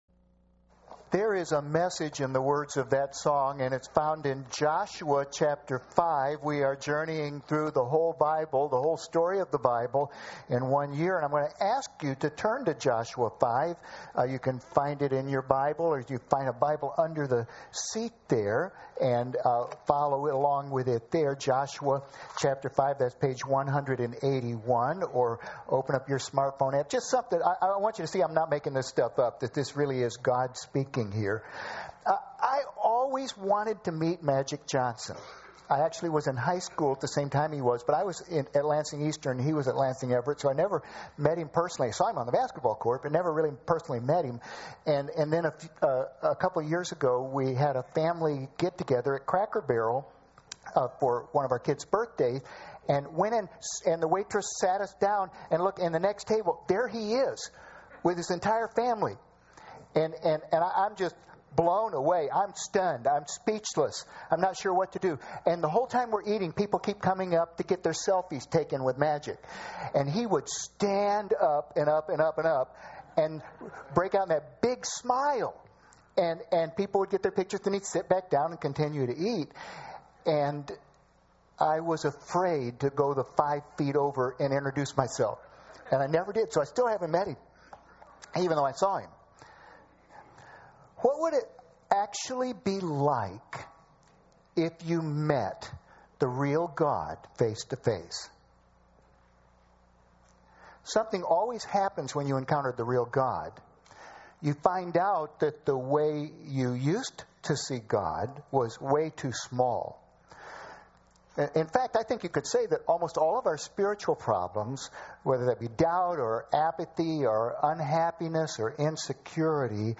The Whole Story Passage: Joshua 1:5-7 Service Type: Sunday Morning Topics